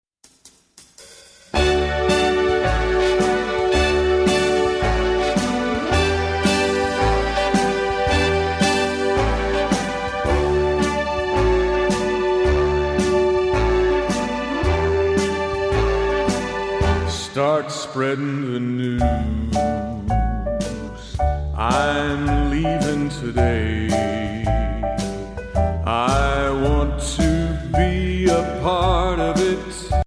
Voicing: PVG Collection